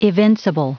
Prononciation du mot evincible en anglais (fichier audio)
Prononciation du mot : evincible